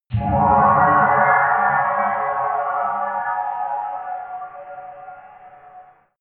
Gemafreie Sounds: Impacts